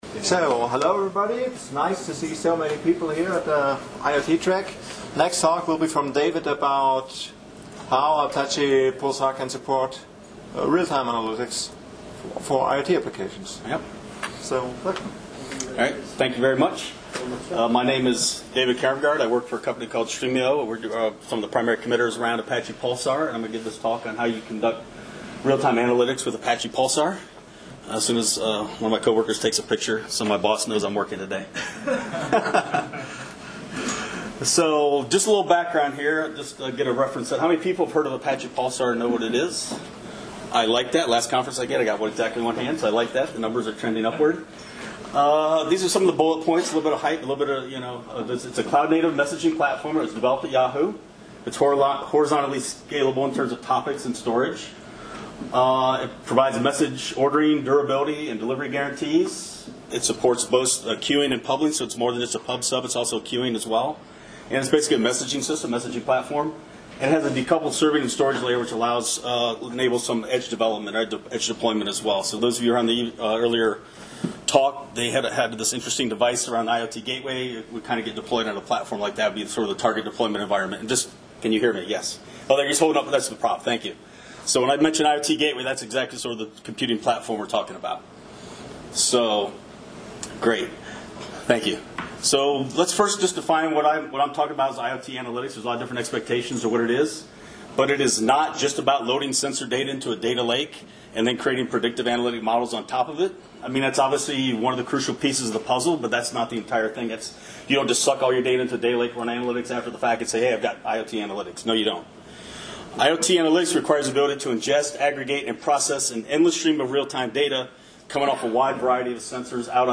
In this talk, we will present a solution based on Apache Pulsar Functions that significantly reduces decision latency by using probabilistic algorithms to perform analytic calculations on the edge.